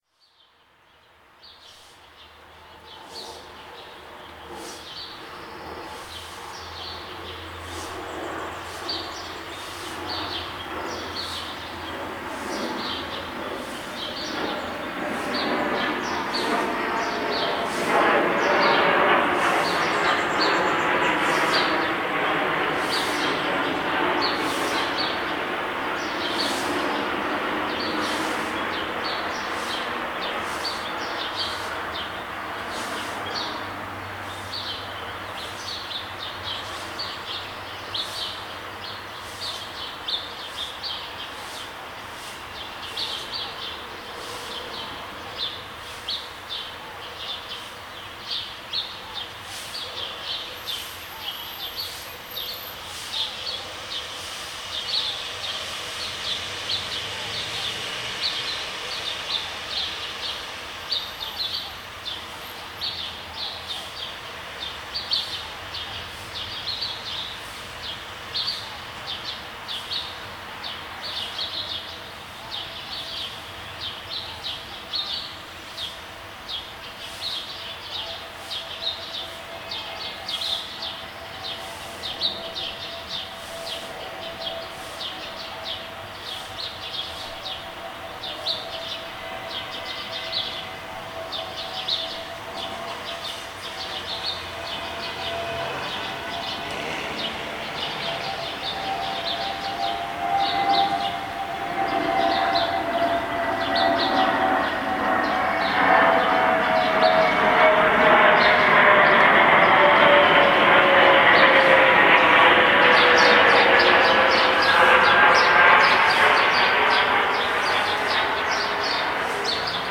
La ciudad con aviones, pájaros y escobas.
Grabación realizada desde mi oficina frente a un parque en el D.F. que se encuentra justamente debajo de la ruta de los aviones que se preparan para aterrizar (aunque el aeropuerto está a 20km y no pasan muy bajo, el ruido llega a ser estresante pero tambien, llegas a no escuchar los aviones que pasan cada 12 minutos).
La grabación se hizo justo entre 8 y 9 de la mañana, a la hora que los conductores se vuelven locos y el tráfico es terrible.